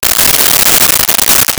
Plastic Wrapper 03
Plastic Wrapper 03.wav